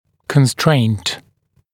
[kən’streɪnt][кэн’стрэйнт]ограничение, ограничивающее условие